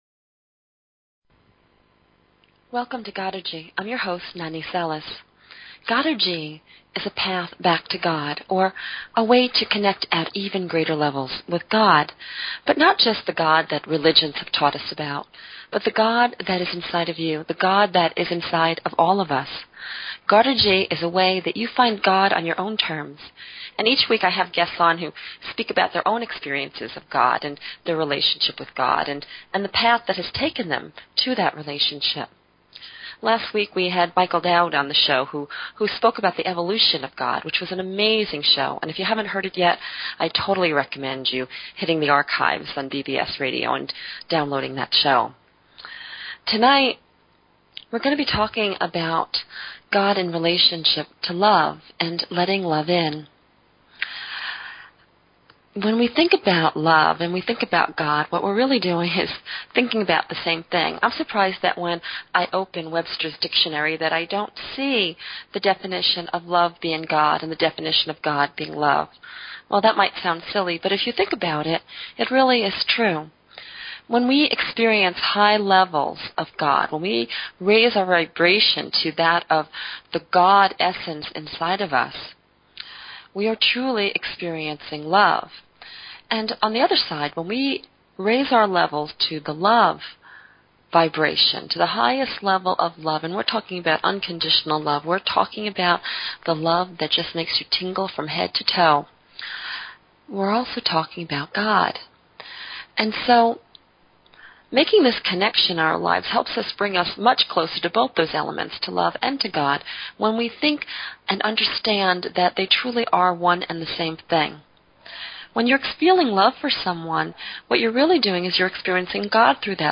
Talk Show Episode, Audio Podcast, Godergy and Courtesy of BBS Radio on , show guests , about , categorized as